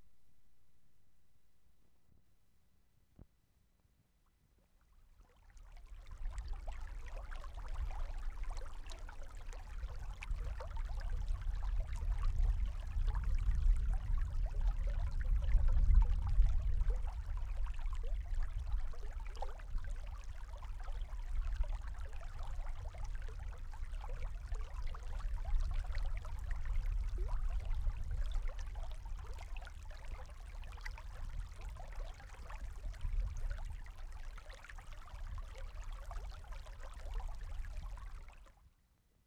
ATHABASCA RIVER, JASPER, ALBERTA Sept. 30, 1973
ATHABASCA RIVER, close up from edge of bank 0'33"
2. Some wind on microphones